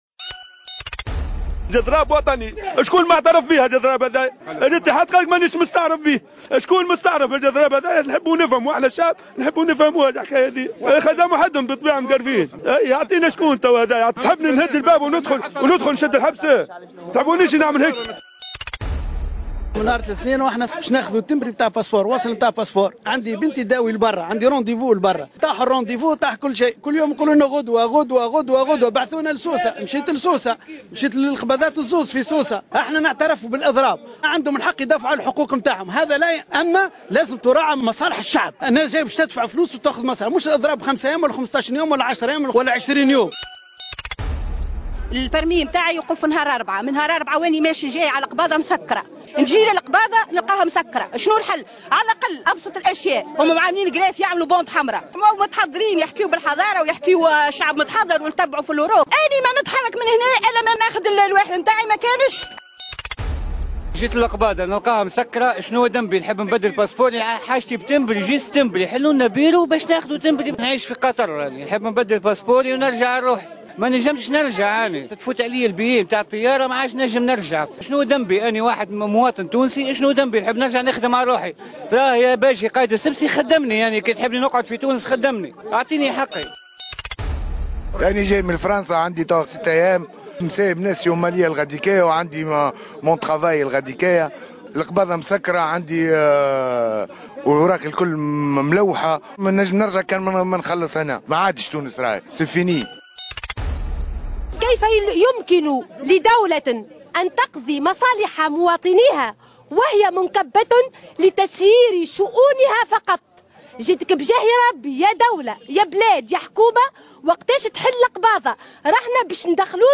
روبورتاج اضراب القباضات في المنستير